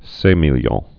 (sāmēl-yôɴ)